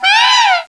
pokeemerald / sound / direct_sound_samples / cries / liepard.aif